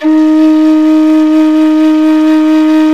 SULING VIB00.wav